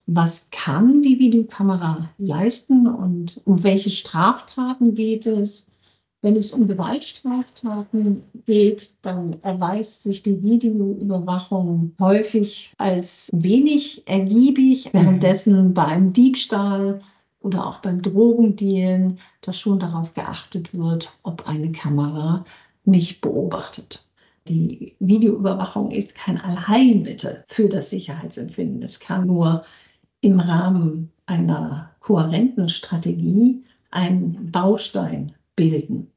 Interview: Wie sinnvoll ist Videoüberwachung an öffentlichen Plätzen?